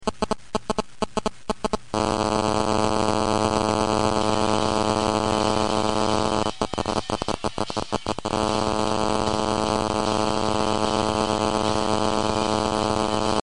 Cell Phone RF Interference
Have you ever left your cell phone next to an unshielded speaker and heard that awful buzzing noise?
cell_buzz.mp3